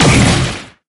rhino_fire_01.ogg